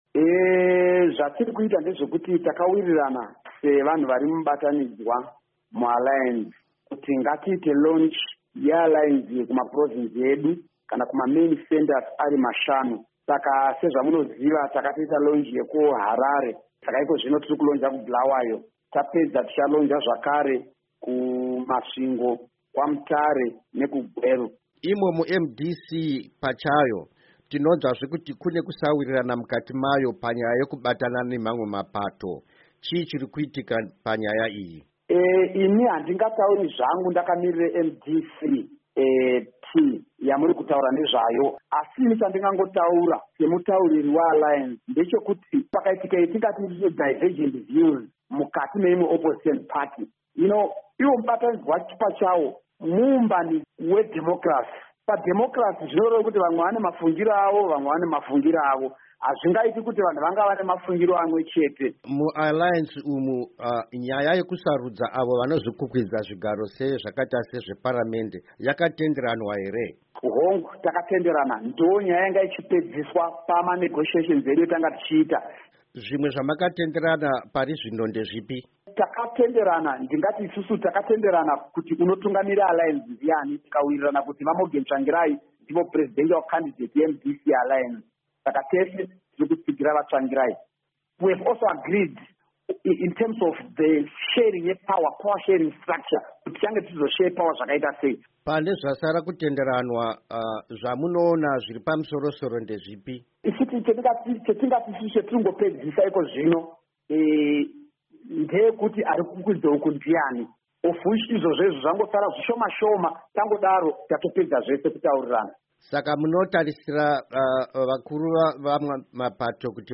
HURUKURO NAVA JACOB NGARIVHUME